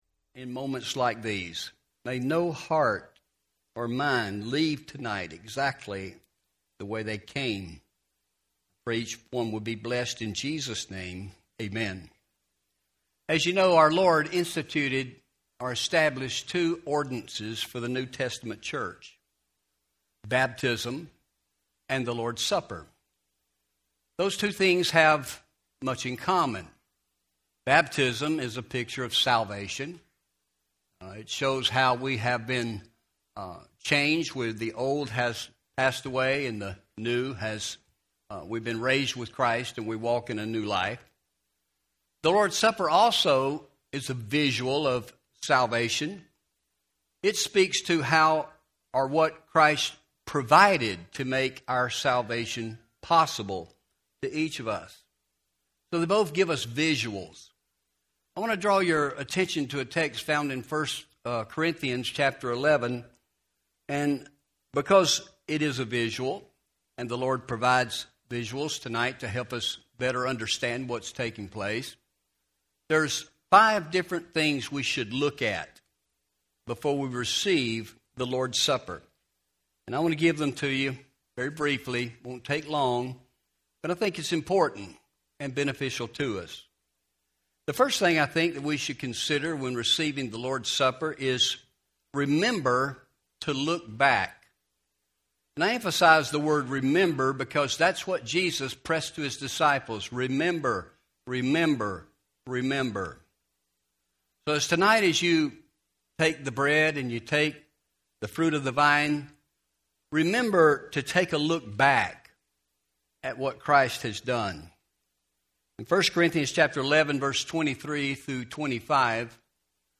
Home › Sermons › How To Receive The Lord’s Supper